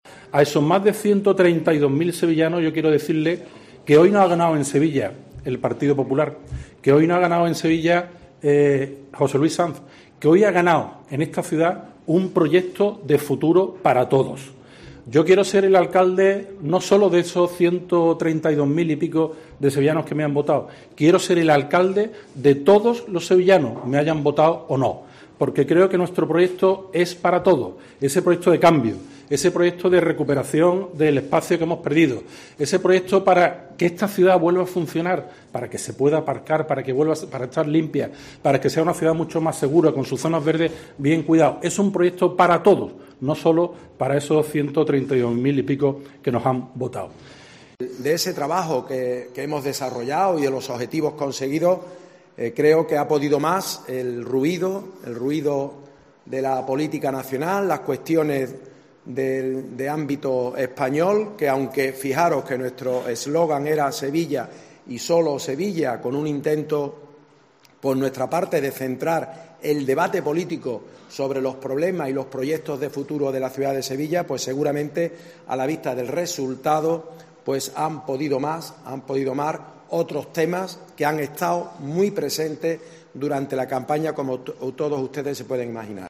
Valoraciones en la noche electoral de José Luis Sanz del PP y Antonio Muñoz del PSOE